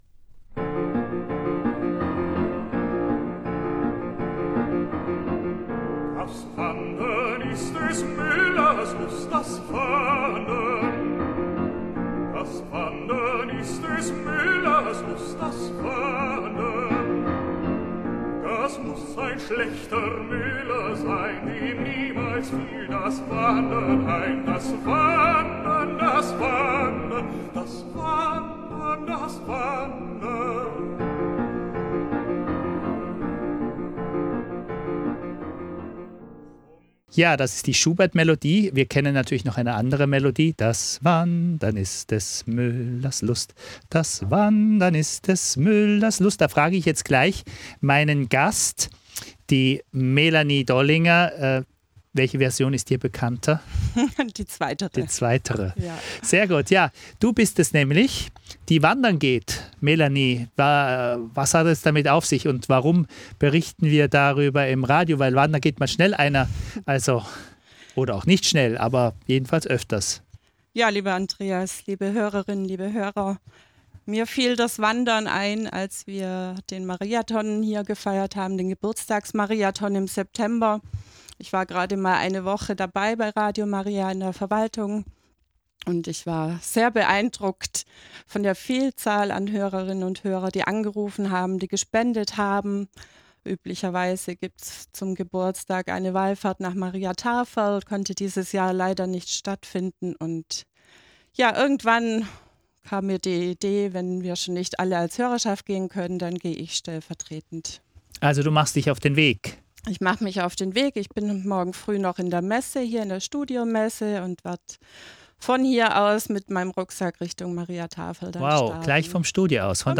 Vorbereitung Off und On Air
Auf Sendung erzählte sie, wieso ihr die Fußwallfahrt am Herzen liegt und dass sie eine sehr erfahrene Pilgerin und Wanderin ist.